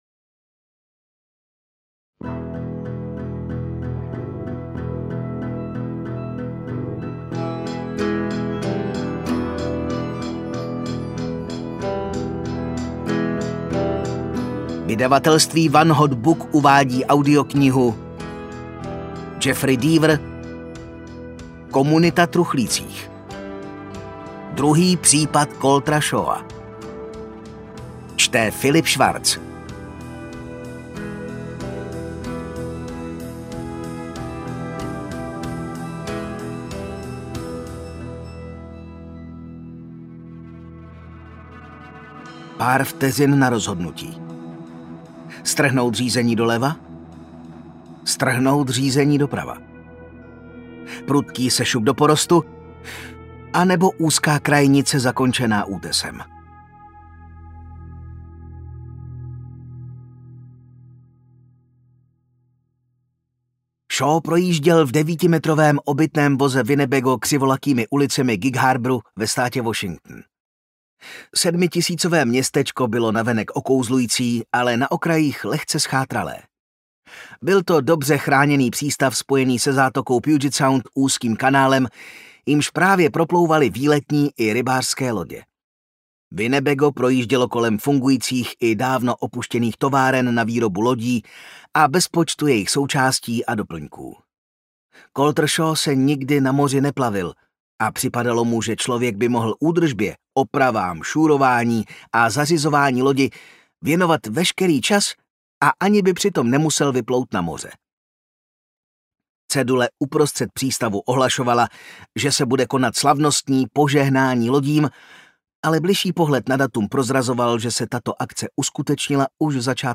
Komunita truchlících audiokniha
Ukázka z knihy